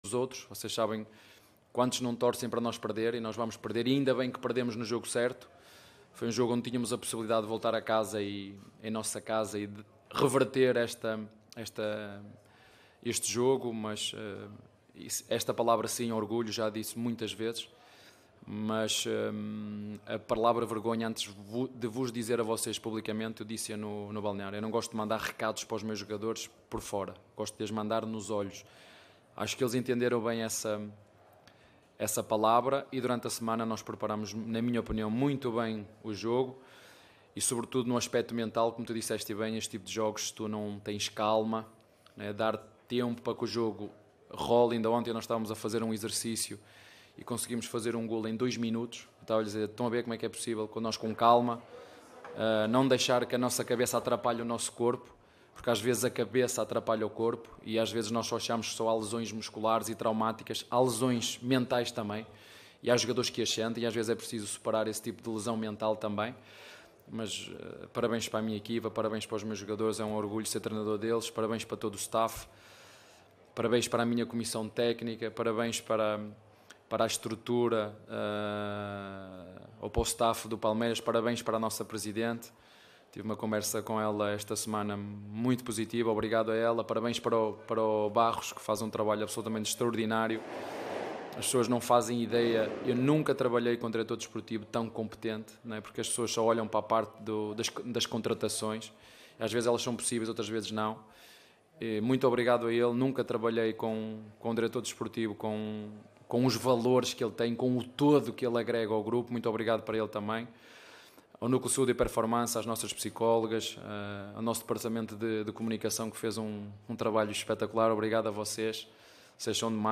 COLETIVA-ABEL-FERREIRA-_-PALMEIRAS-CAMPEAO-PAULISTA-2023.mp3